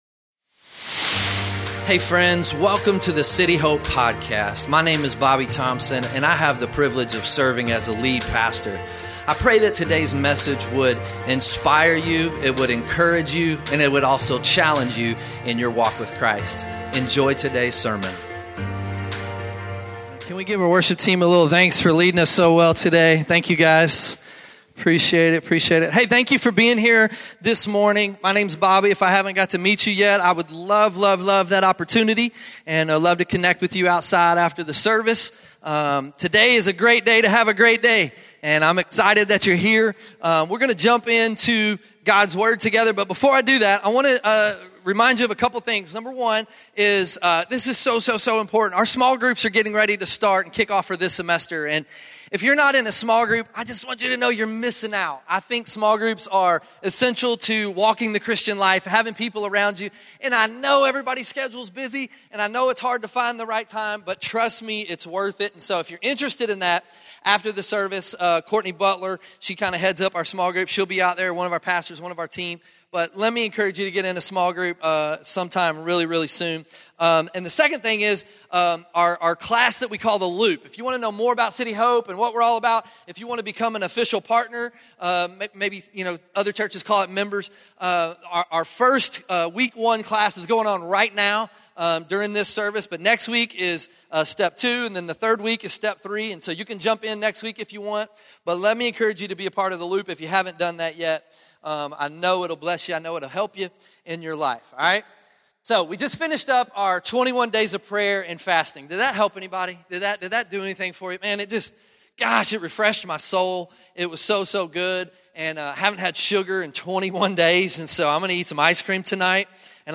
2024 Worship Worship Sunday Morning In our first week of our Worship series